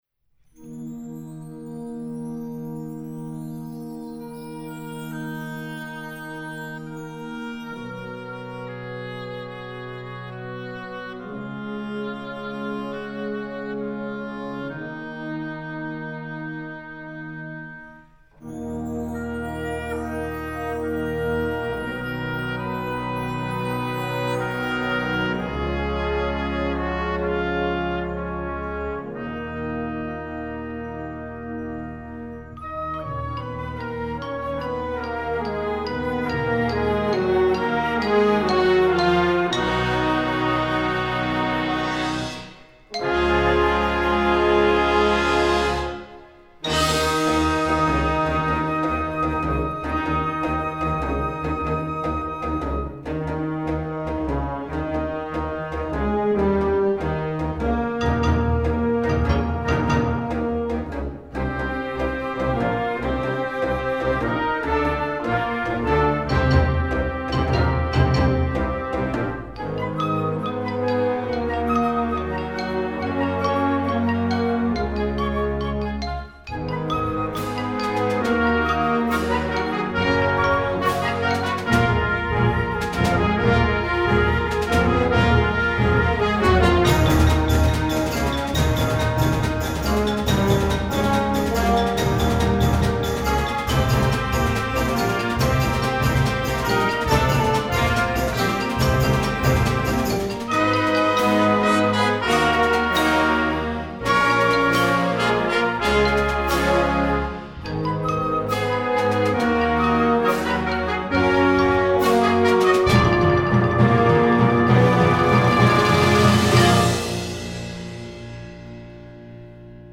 Gattung: Konzertwerk
23 x 30,5 cm Besetzung: Blasorchester PDF